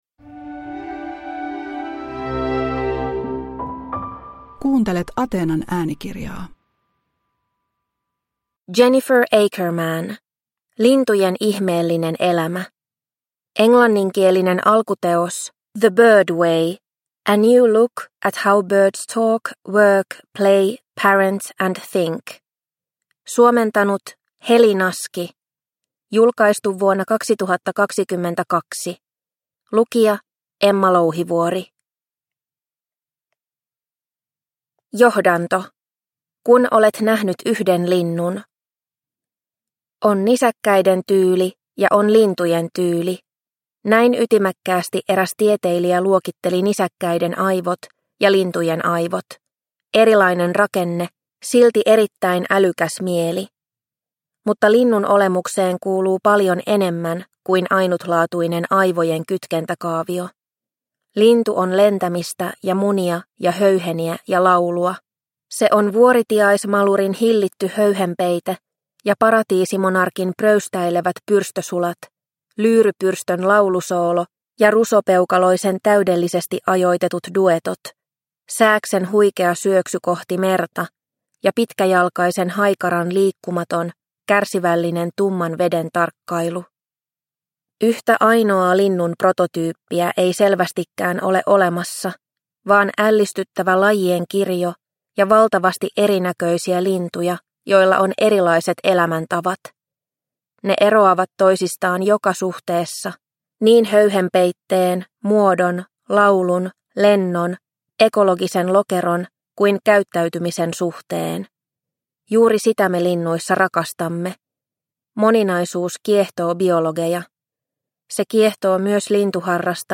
Lintujen ihmeellinen elämä – Ljudbok – Laddas ner